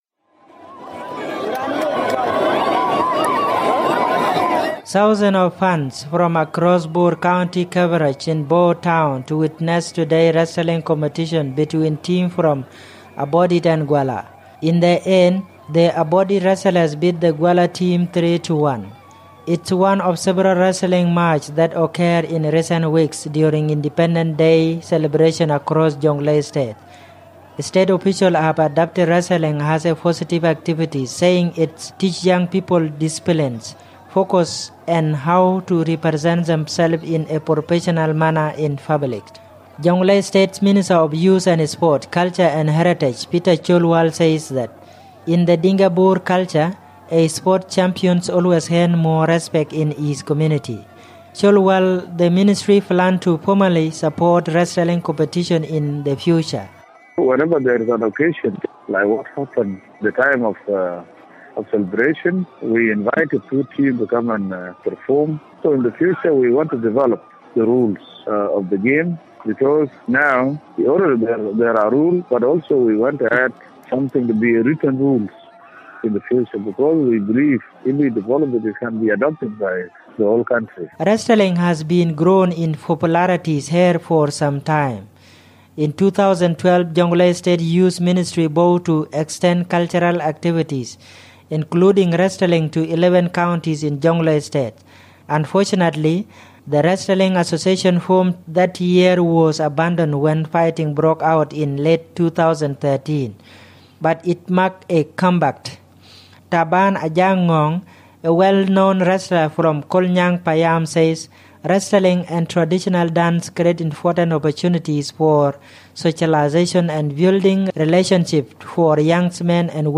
reports from Bor.